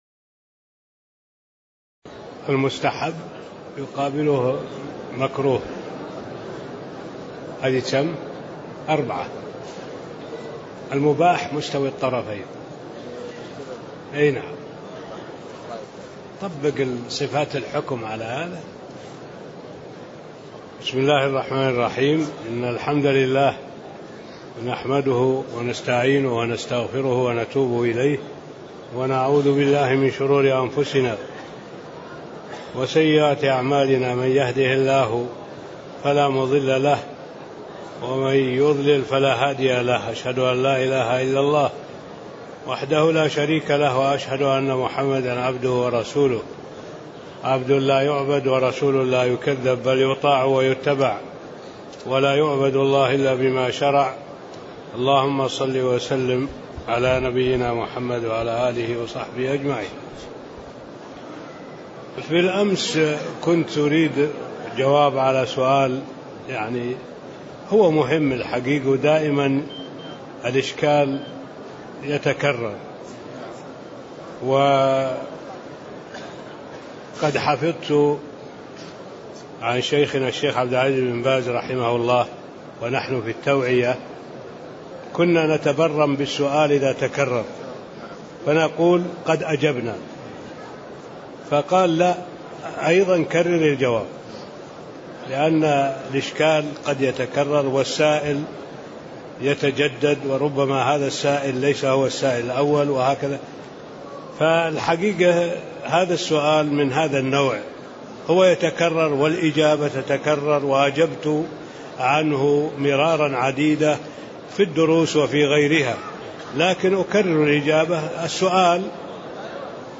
تاريخ النشر ٢٠ جمادى الأولى ١٤٣٤ هـ المكان: المسجد النبوي الشيخ: معالي الشيخ الدكتور صالح بن عبد الله العبود معالي الشيخ الدكتور صالح بن عبد الله العبود فصل أركان الصلاة (06)